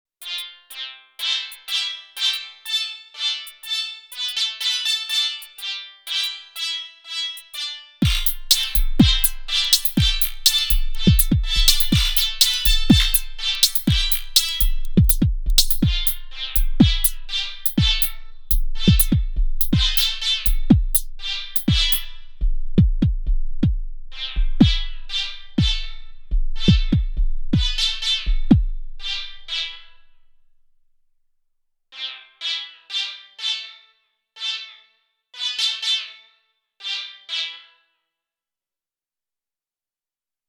Science Labs : Swarm (the subject) but also Stereo Image and Polyphony.
I wanted to express the Polyphonic and beautiful sides of the Syntakt through the Swarm Machines.